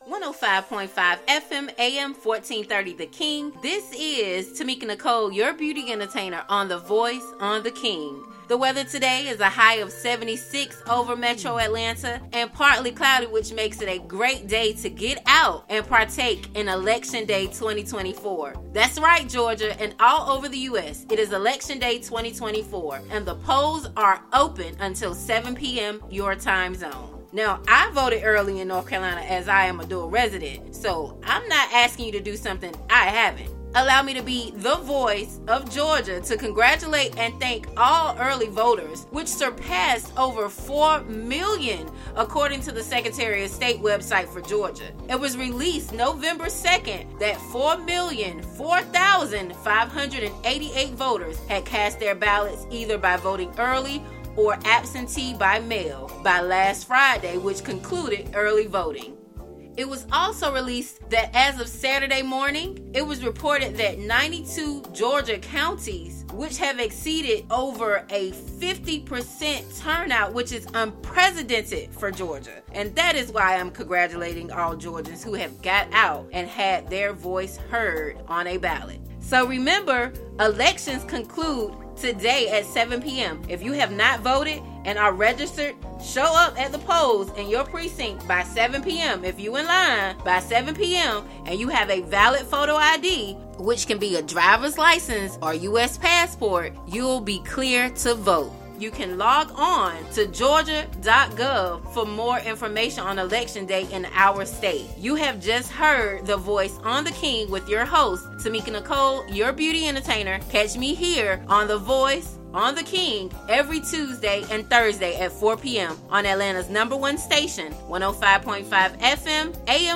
The Voice is a 2 Part Segment Show where local and national leaders share their stories with the world!
Heard on 105.5 FM/AM 1430 & AM 1010 The King